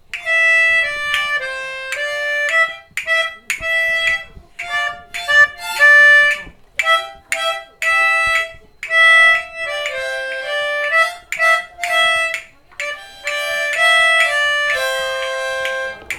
합주.ogg